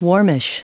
Prononciation du mot warmish en anglais (fichier audio)
Prononciation du mot : warmish